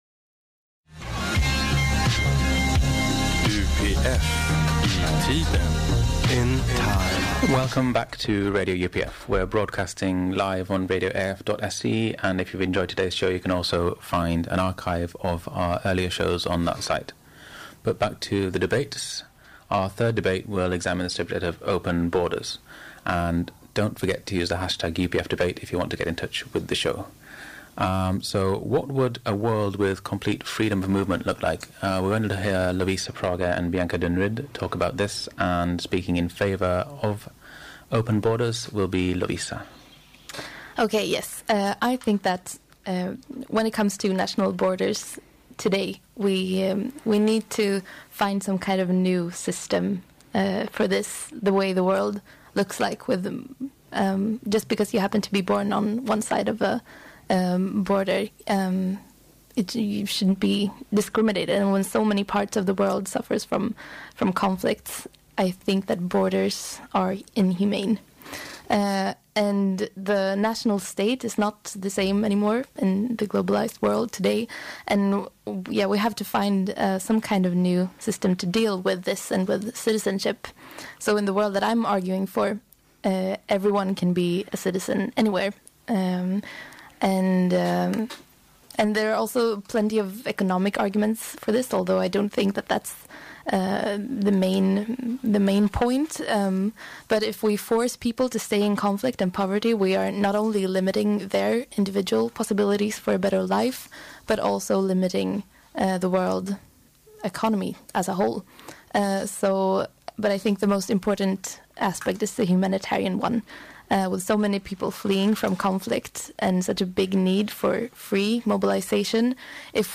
Live debate: World without borders